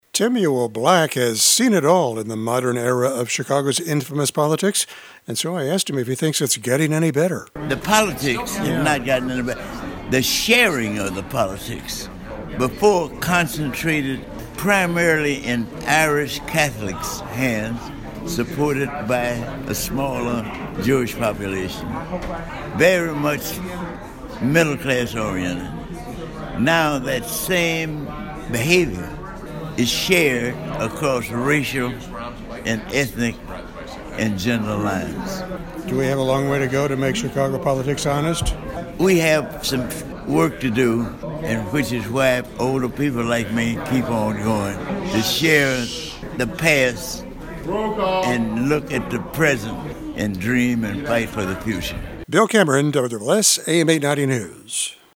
(CHICAGO)  At City Hall, one of the icons of Chicago’s African-American politics – Dr. Timuel Black – is 100 years olD.